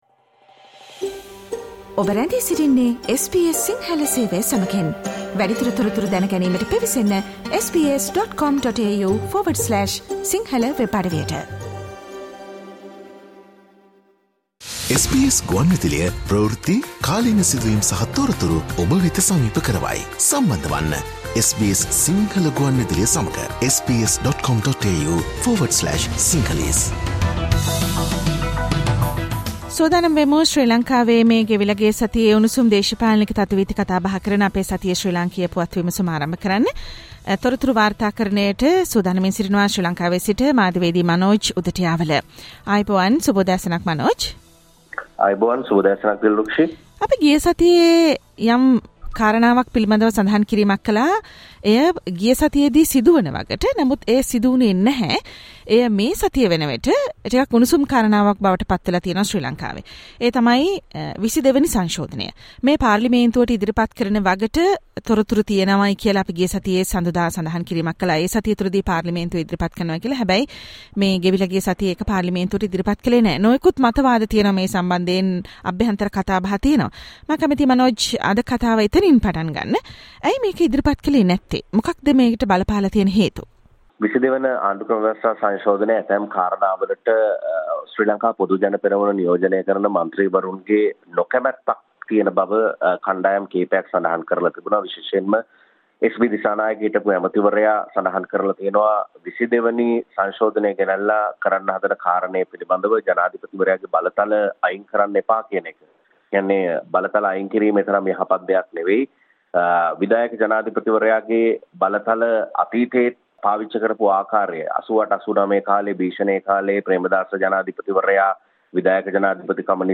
Sri Lankan PM Dinesh Gunawardhana and the president Ranil Wickramasinghe_ SBS Sinhala weekly political highlights
SBS Sinhala radio brings you the most prominent news highlights of Sri Lanka in this featured current affair segment every Monday